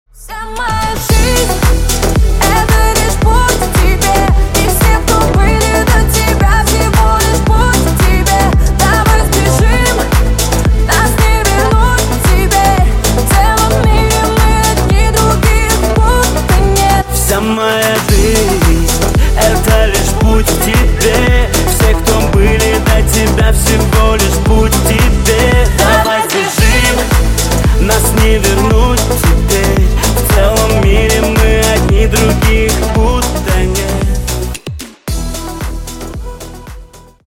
Поп Рингтоны